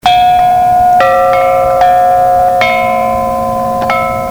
Each bell produces three tuned notes: the larger the windbell the deeper the pitches and richer the tones.
The 3 dot windbell's (13") pitches are in the mid range and rich.